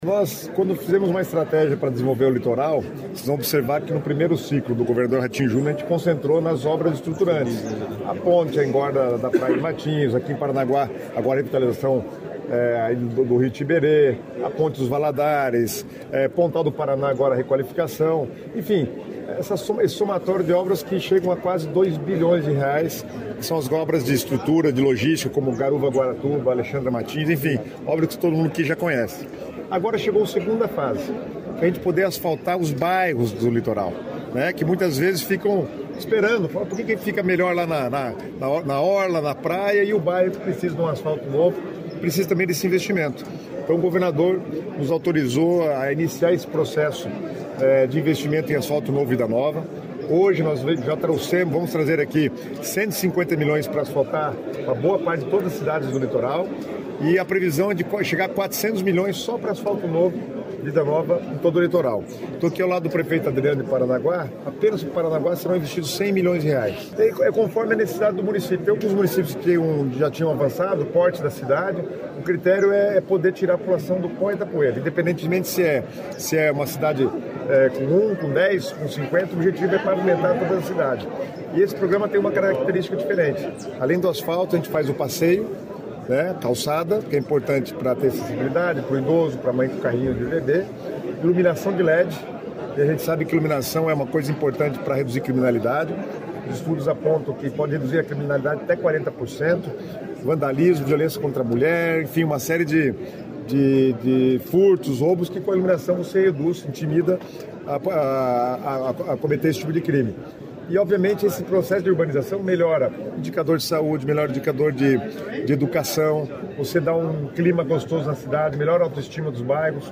Sonora do secretário Estadual das Cidades, Guto Silva, sobre os repasses de R$ 150 milhões para a infraestrutura do Litoral